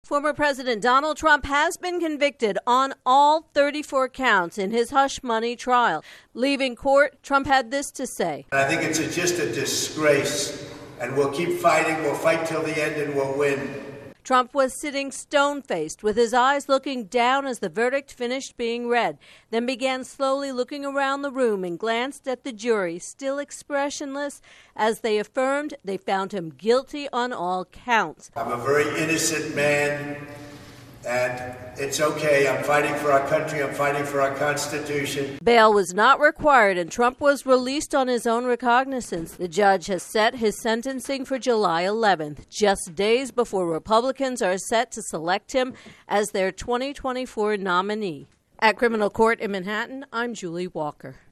reports from court.